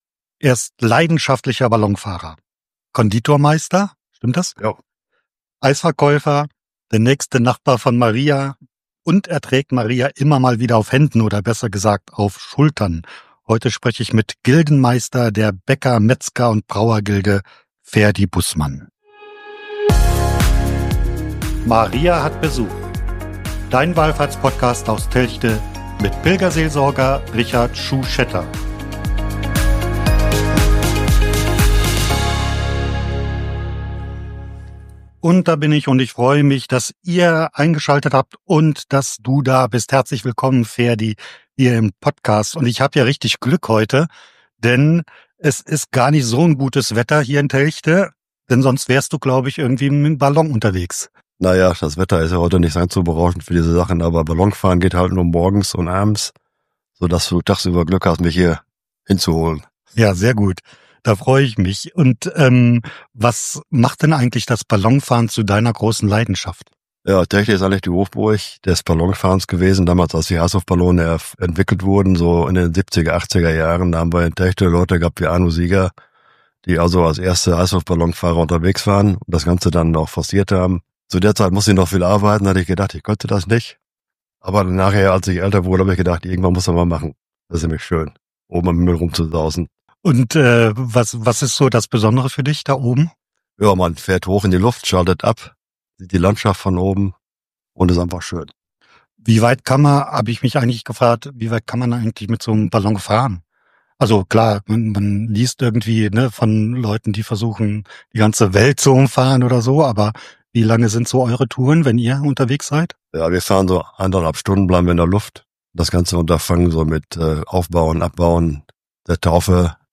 Die Mitglieder der Bäcker-Metzger-Brauer-Gilde haben seit 350 Jahren das Verbriefte Recht, bei Prozessionen und anderen Gelegenheiten unser Gnadenbild zu tragen. Im Gespräch